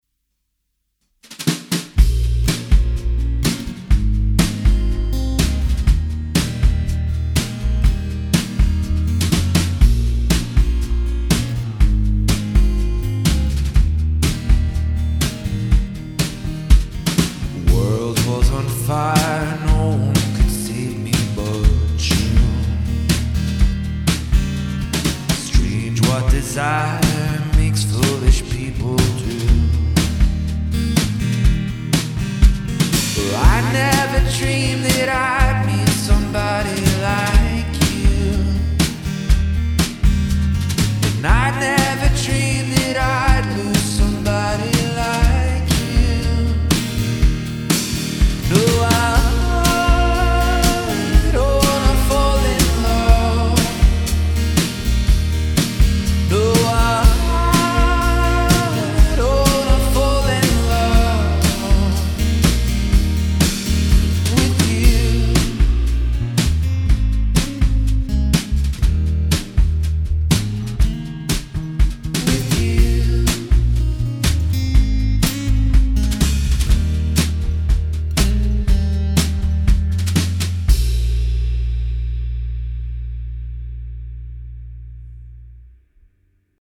thrilling upbeat folk outfit
folk pop outfit
fiddle